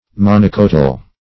Monocotyle \Mon"o*co*tyle\, a.
monocotyle.mp3